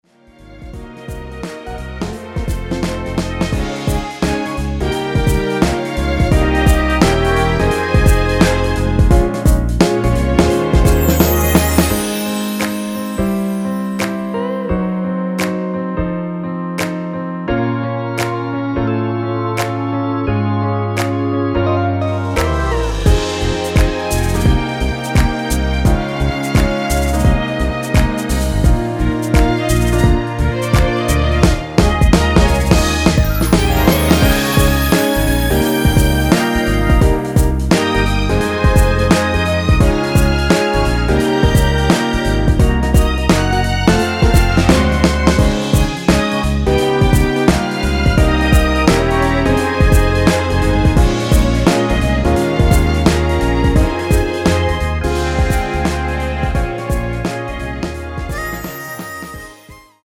엔딩이 페이드 아웃이라서 노래하기 편하게 엔딩을 만들어 놓았으니 코러스 MR 미리듣기 확인하여주세요!
원키(1절+후렴)으로 진행되는 MR입니다.
Ab
앞부분30초, 뒷부분30초씩 편집해서 올려 드리고 있습니다.